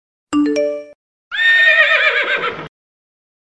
Nada dering suara Kuda Meringkik
Genre: Nada dering binatang
nada-dering-suara-kuda-meringkik.mp3